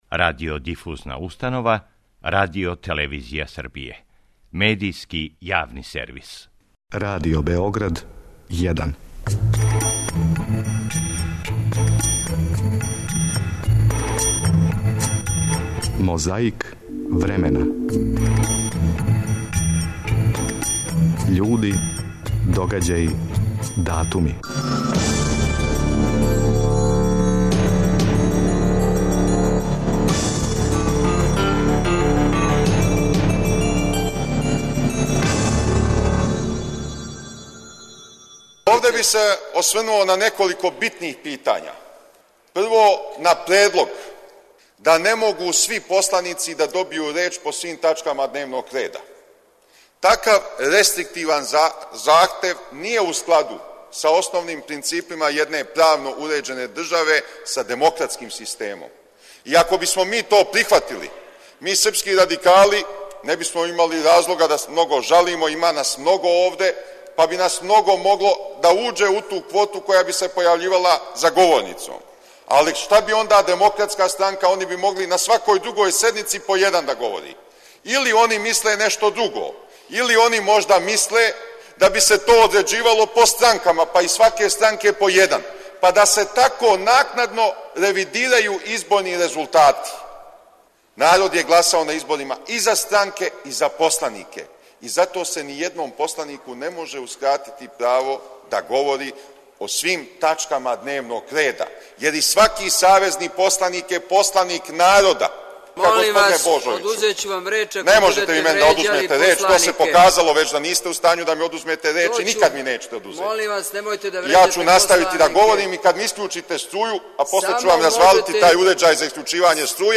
Борећи се против пилећег памћења подсећамо како су полемисали Војислав Шешељ и Радоман Божовић, на заседању Скупштине Савезне републике Југославије, одржаном 18. марта 1994. године.